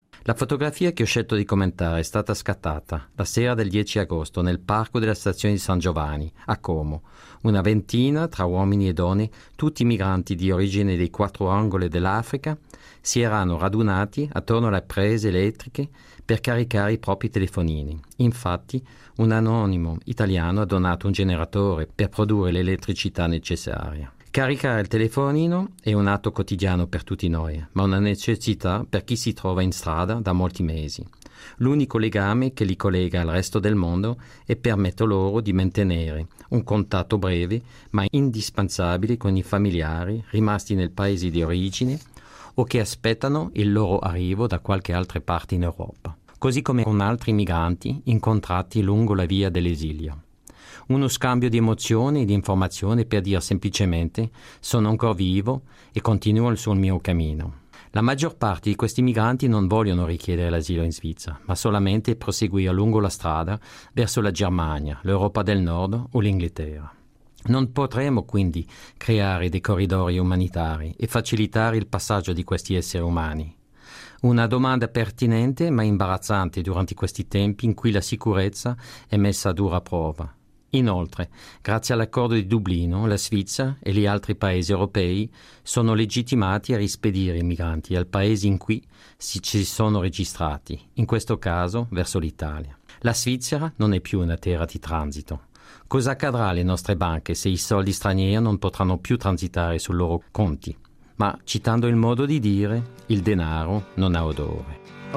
A radio comment about the image “African migrants in Como, Italy”.